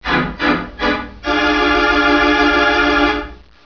Suspense Music Sound Effect Free Download
Suspense Music